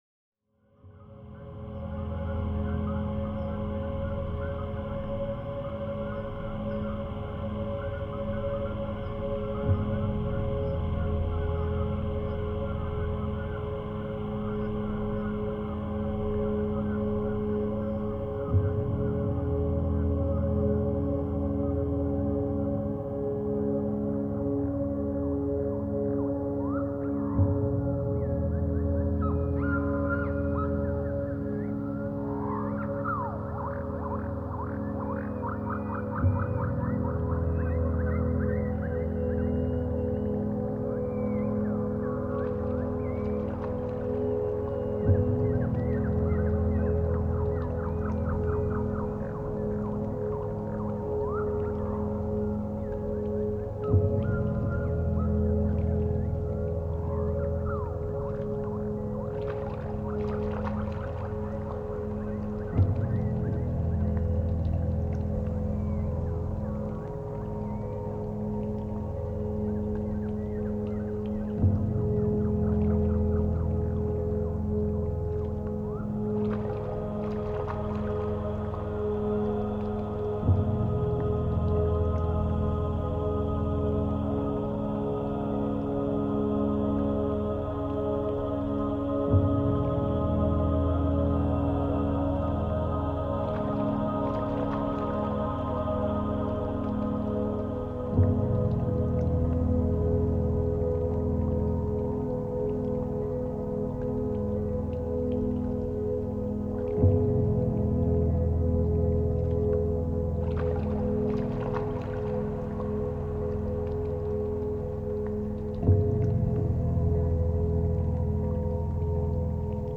Вторая часть трилогии эмбиентной музыки
голос, флейта, полевые записи